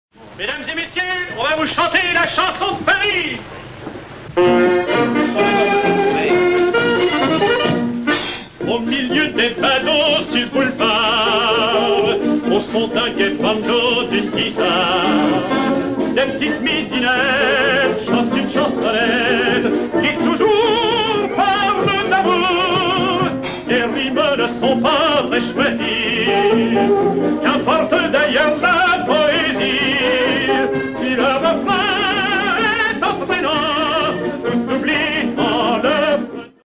extrait du film